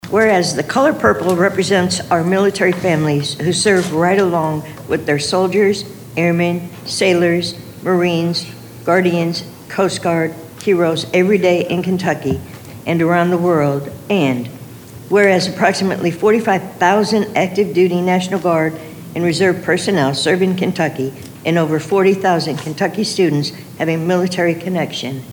Christian County Fiscal Court opened Tuesday’s meeting celebrating “Month of the Military Child,” while proclaiming Wednesday, April 15, a “Purple Up Day” across the community.